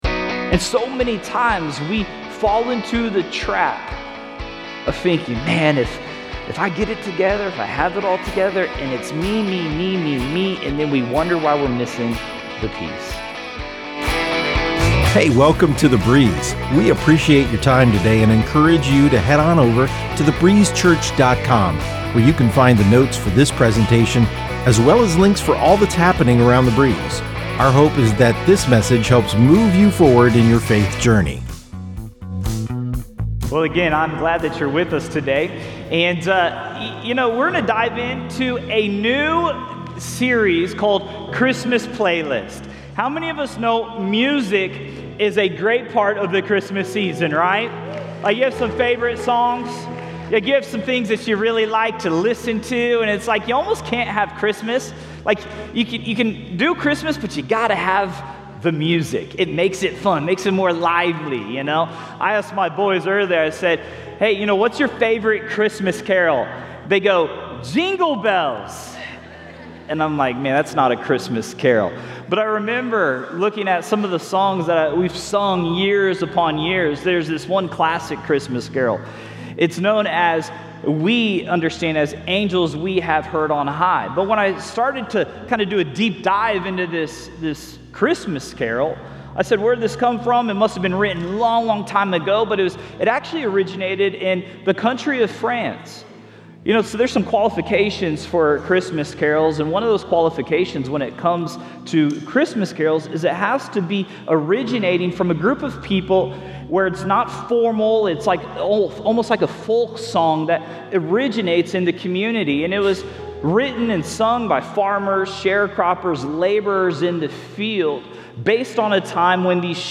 Thank you for enjoying this life changing message from The Breeze Church.